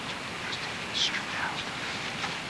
This EVP was recorded at the Ogden City Cemetery, which supposedley has a rich history of being haunted. We were just walking to our cars after the investigation and we recorded this.
whispers2.wav